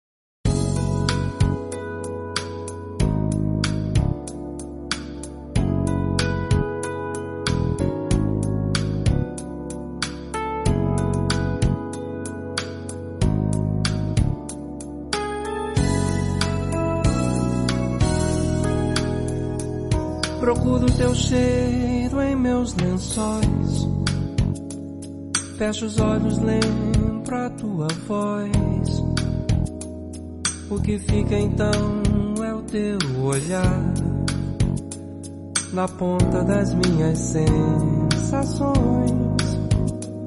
With a velvet voice gives us a romantic journey.